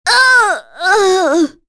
Maria-Vox_Dead.wav